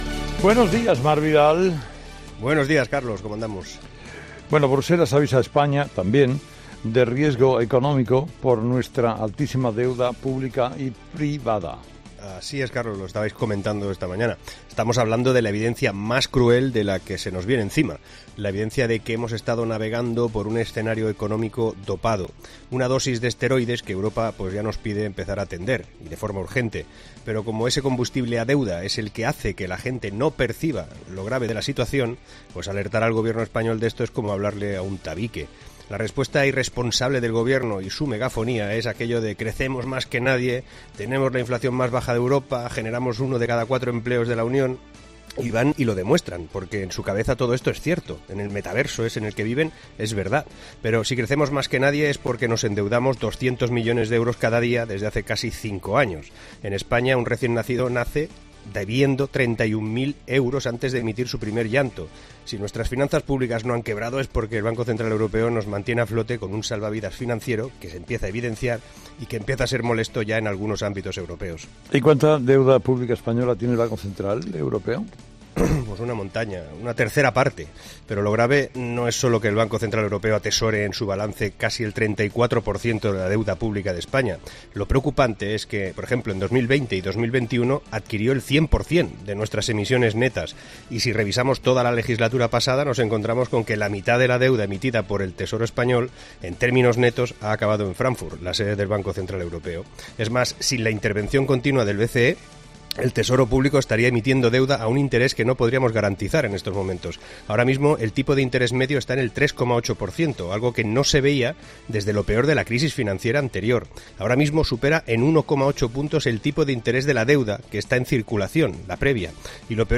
El analista económico explica las consecuencias del aviso de Bruselas de riesgo económico: "La evidencia más cruel de lo que se nos viene encima"